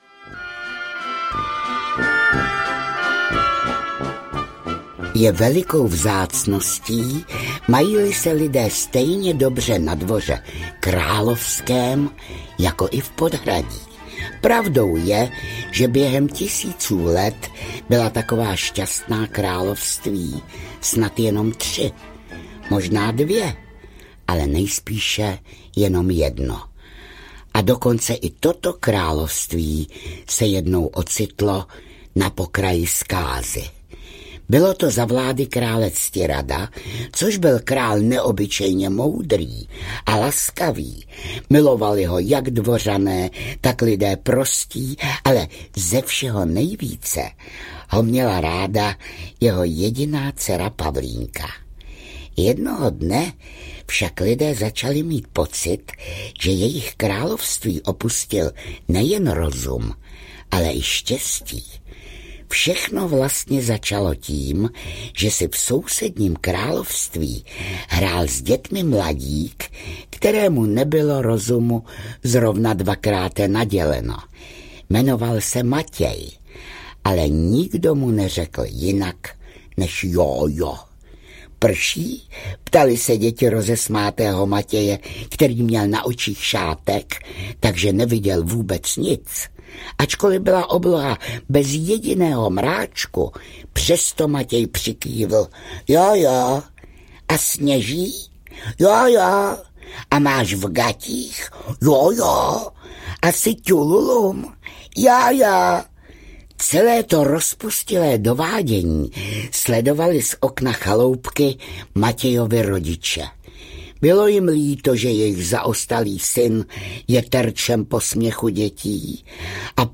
Nesmrtelná teta audiokniha
Ukázka z knihy
• InterpretJiřina Bohdalová